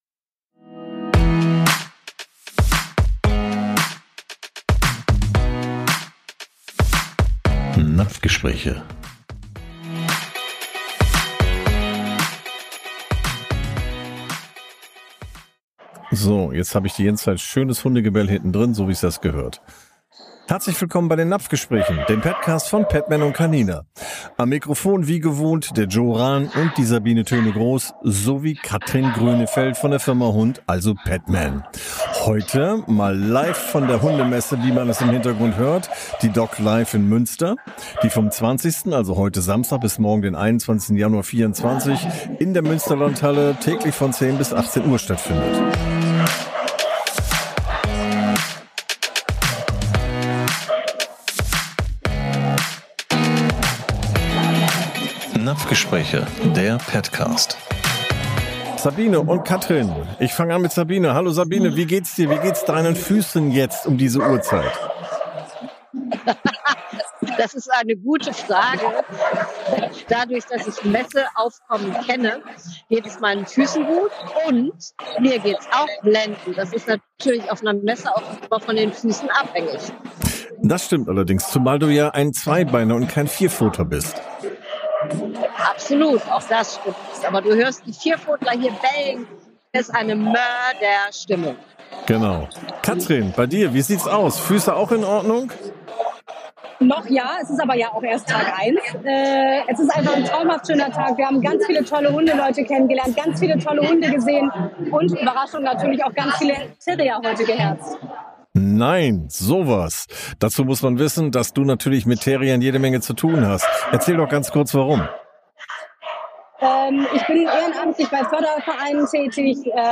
#31 - "DOGLive Messe" Sondersendung ~ Napfgespräche - Experten über Ernährung von Hunden und Katzen und smarte Supplemente Podcast